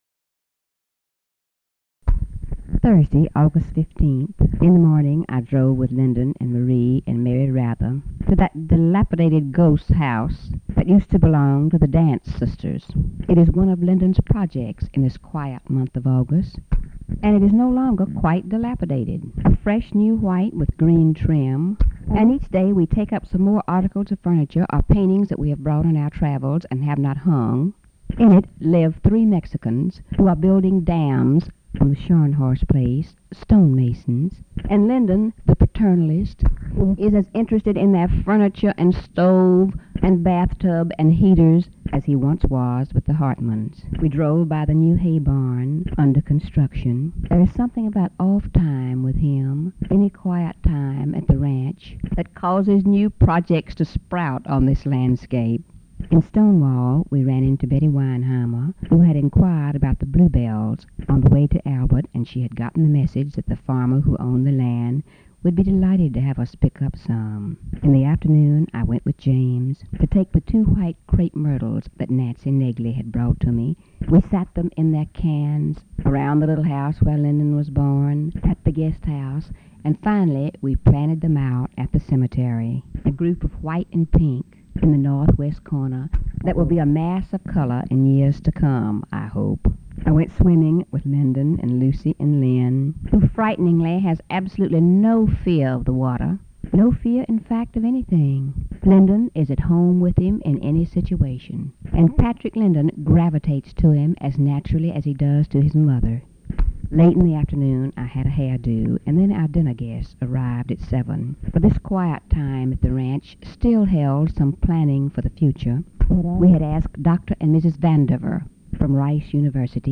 Audio diary and annotated transcript, Lady Bird Johnson, 8/15/1968 (Thursday) | Discover LBJ
LBJ Ranch, near Stonewall, Texas